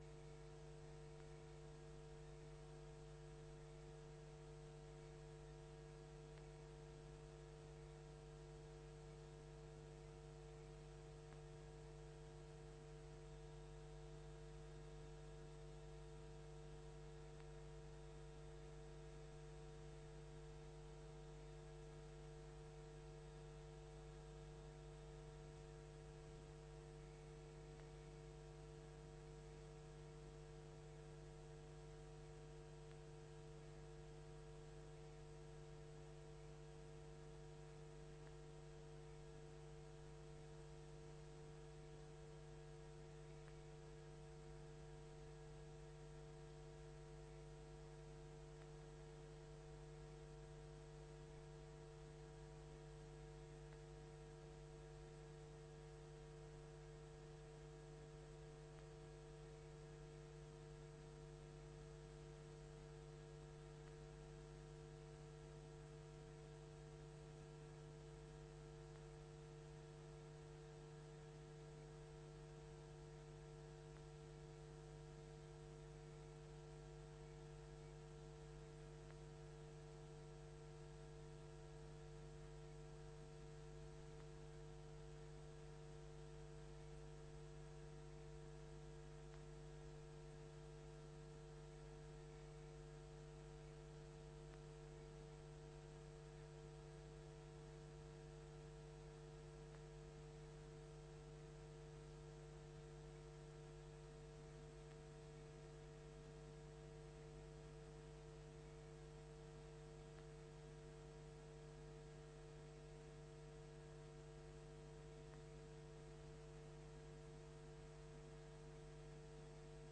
De voorzitter opent de raadscommissievergadering om 19.30 uur.
Locatie: Raadzaal